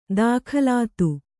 ♪ dākhalātu